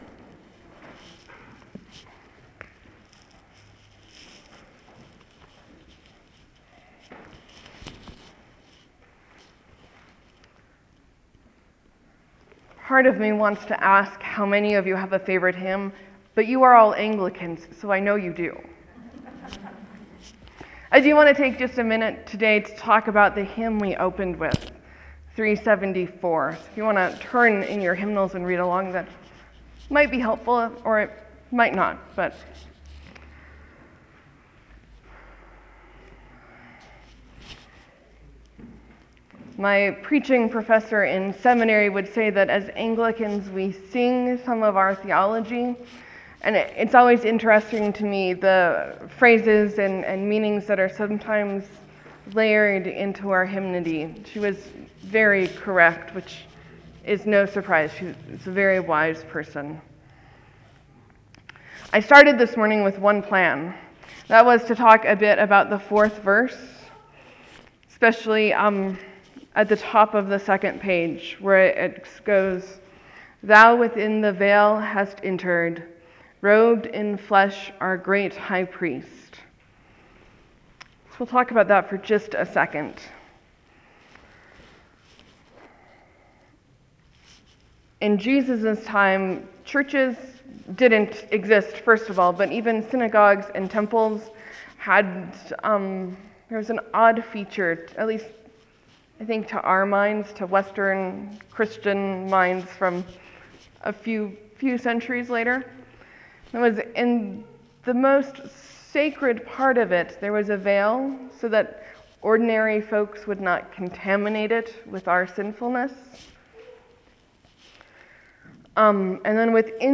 Sermon: Jeremiah does a big and foolish thing, given his situation.